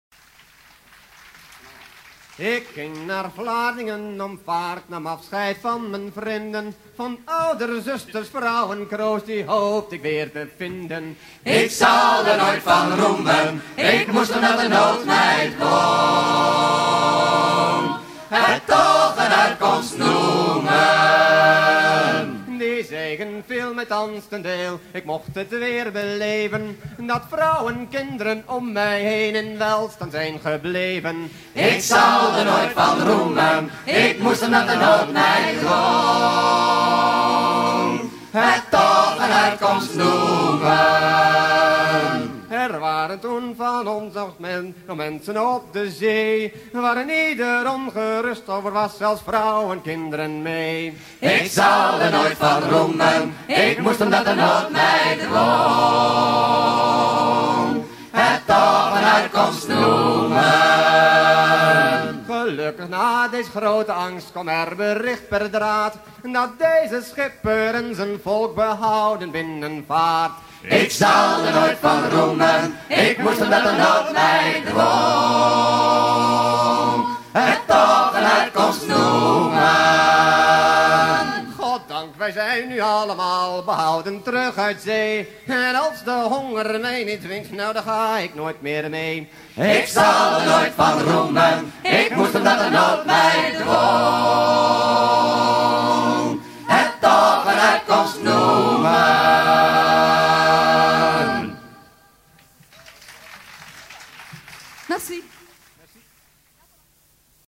chant d'un matelot
Pièce musicale éditée